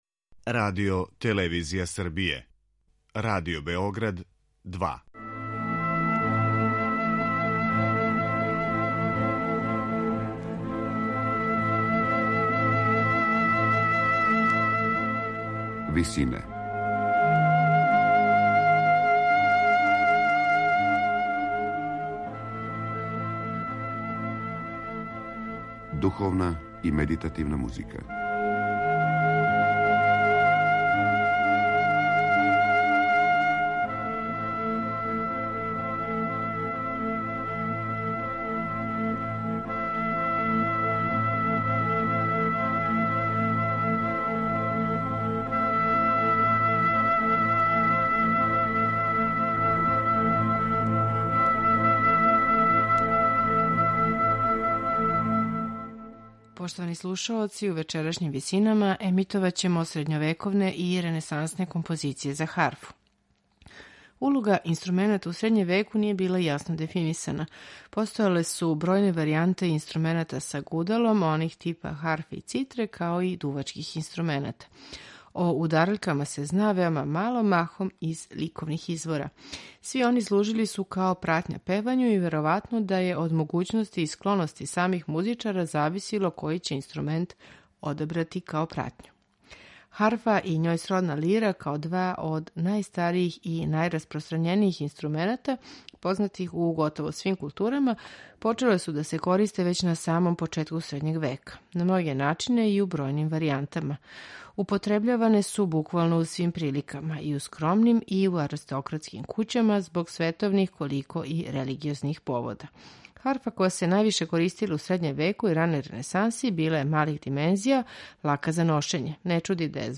Ренесансна харфа
За вечерашње Висине одабрали смо духовне напеве и баладе средњовековних и ренесансних аутора